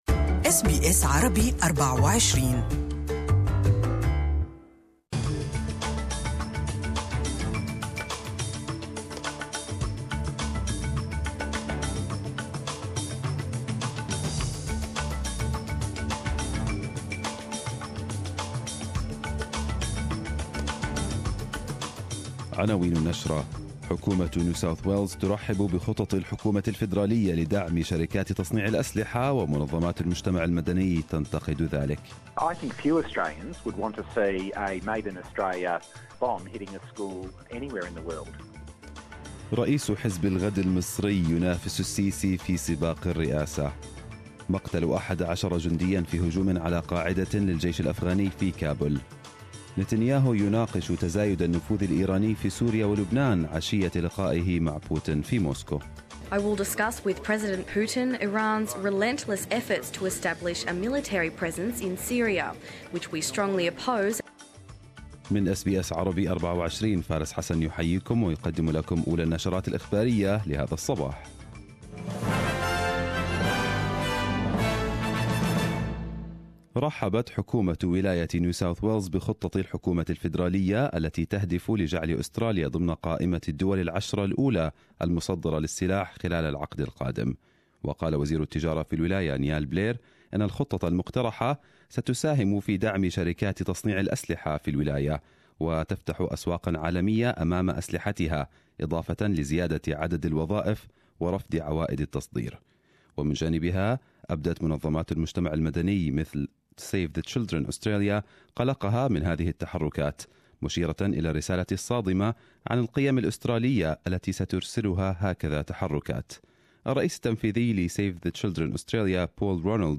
Arabic News Bulletin 30/01/2018